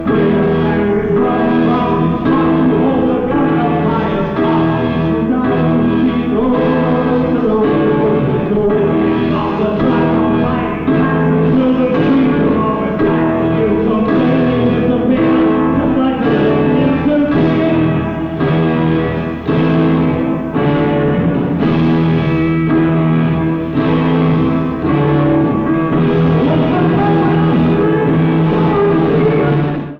Format/Rating/Source: CD - G - Audience
Comments: Horrible audience recording.
Sound Samples (Compression Added):